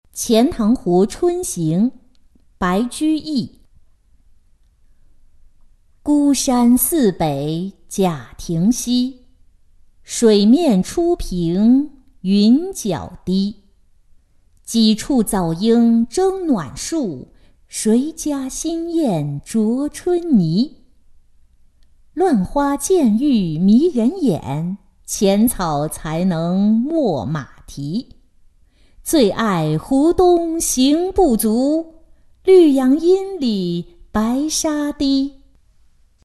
钱塘湖春行-音频朗读